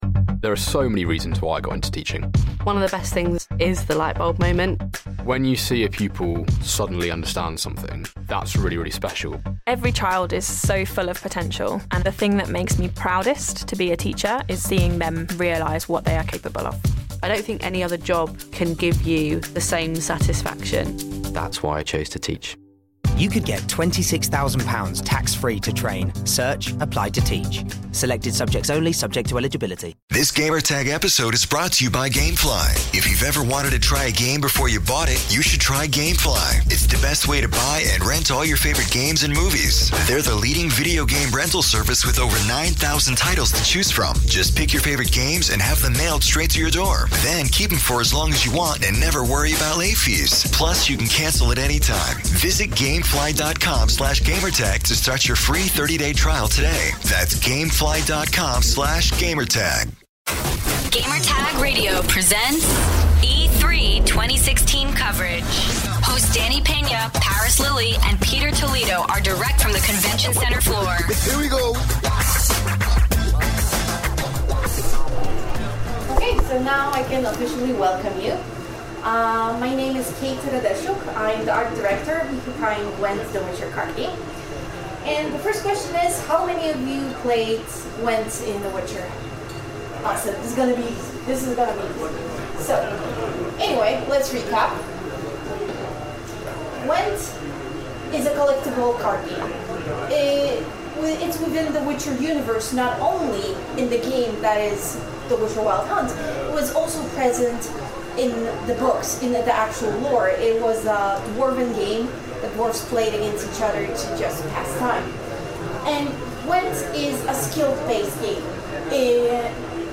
E3 2016: GWENT - The Witcher Card Game Presentation
Presentation for The Witcher Series' standalone card game spinoff.